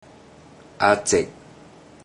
Click each Romanised Teochew word to listen to how the Teochew word is pronounced.
a3zeig0 (variant: a3zeg0)